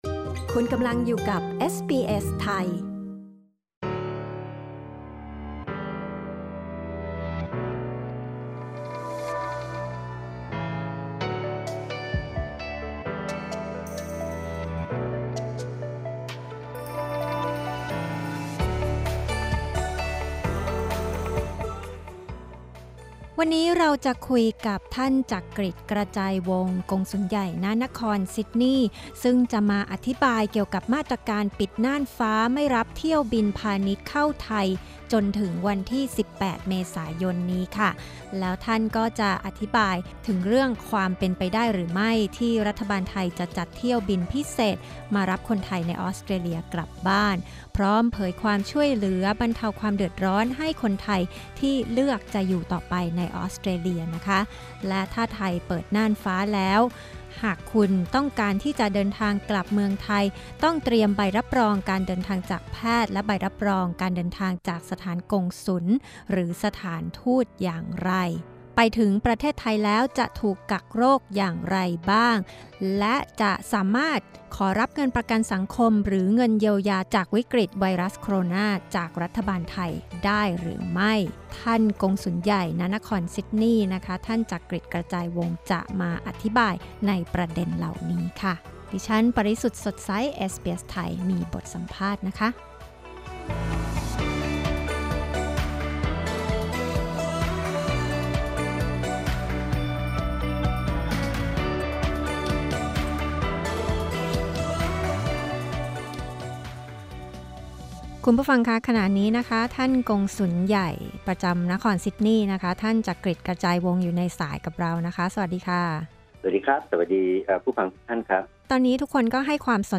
ประเด็นสัมภาษณ์